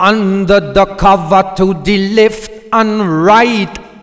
OLDRAGGA5 -R.wav